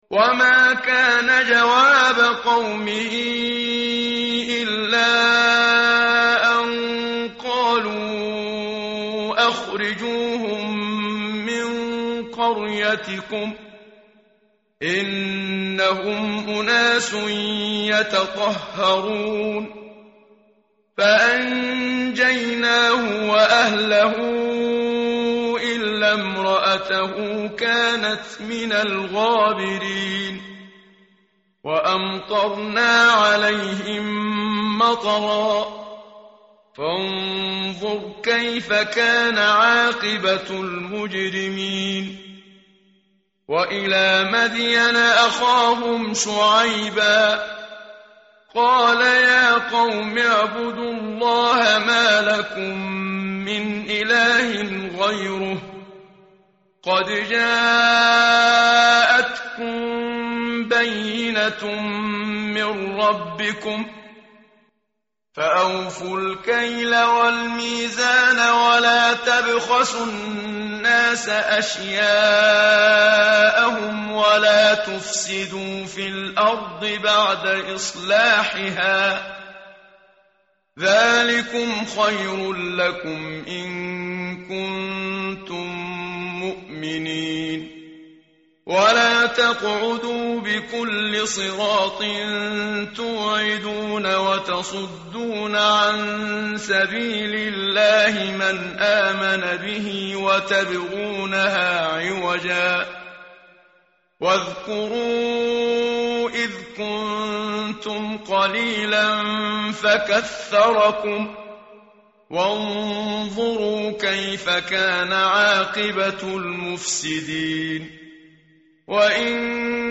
tartil_menshavi_page_161.mp3